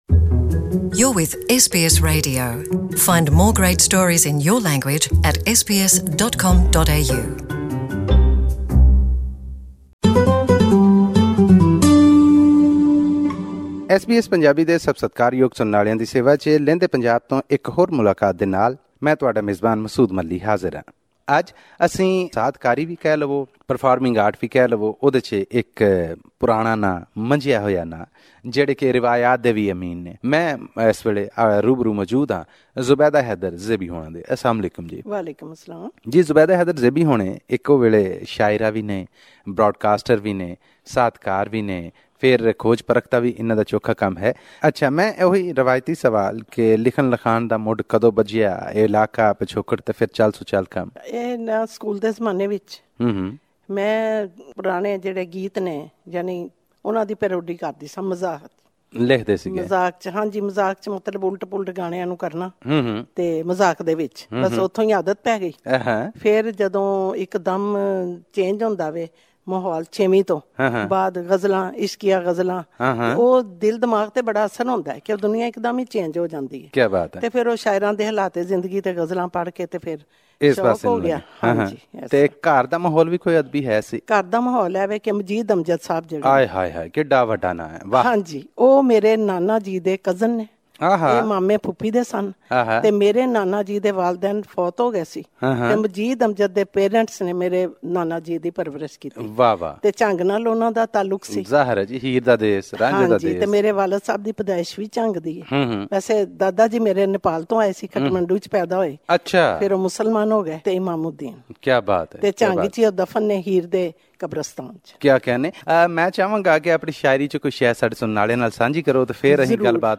In our fortnightly interview series with achievers from Pakistan's Punjab province, this week we bring to you a multifarious talent who can write and sing as well.